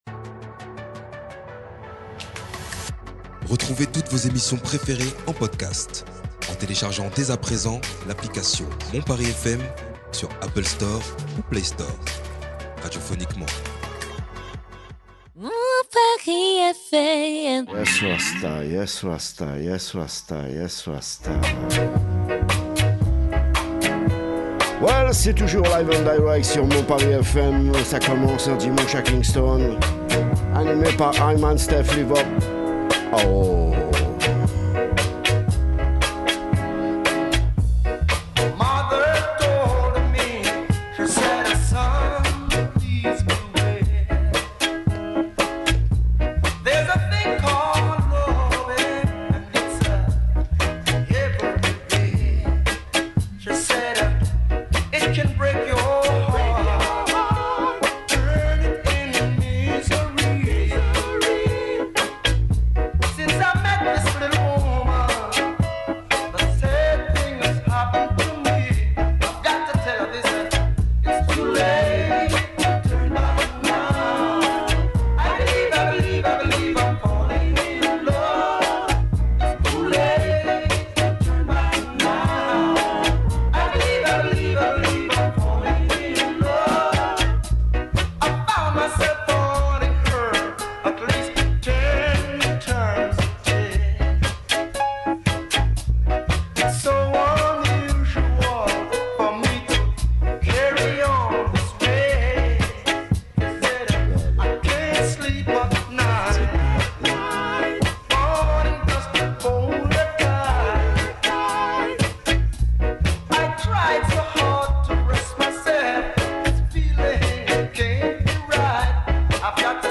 Des tunes Reggae Dancehall d'anthologie !